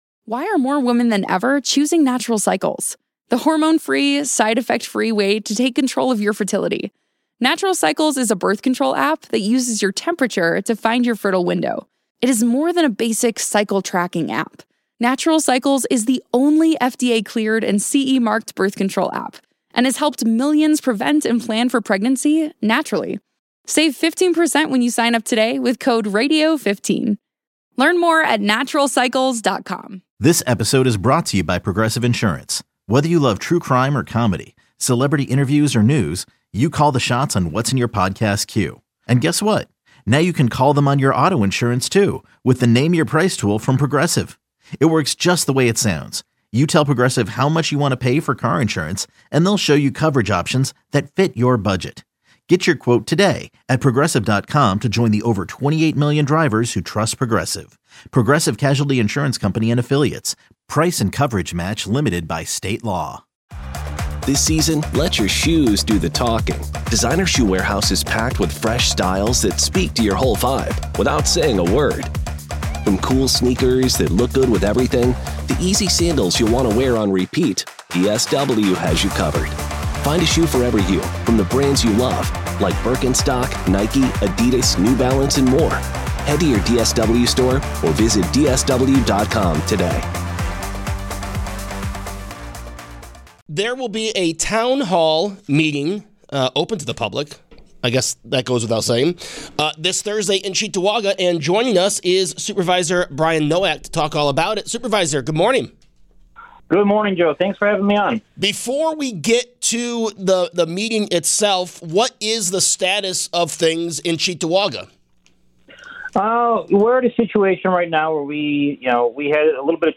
Cheektowaga Supervisor Brian Nowak joins the show to discuss his upcoming Town Hall this Thursday at 6pm.